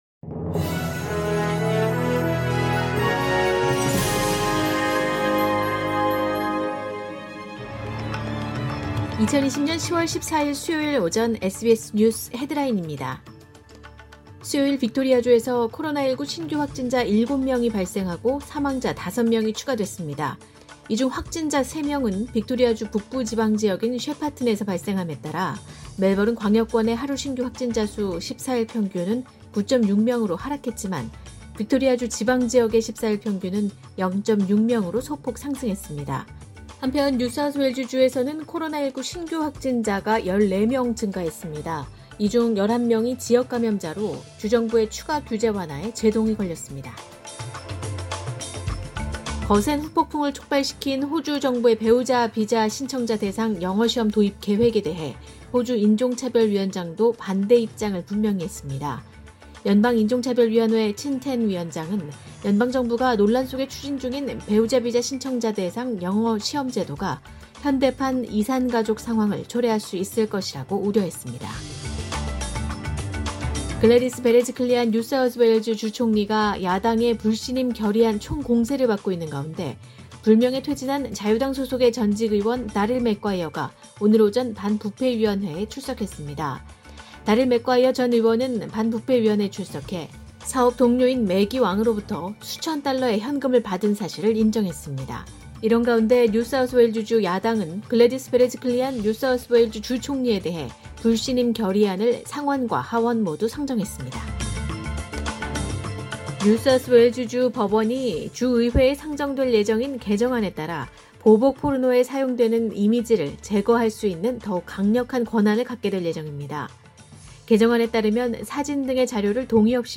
2020년 10월 14일 수요일 오전의 SBS 뉴스 헤드라인입니다.